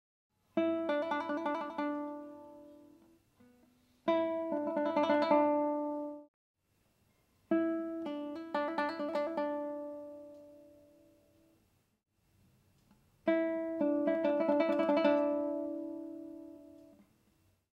16. trilos.m4v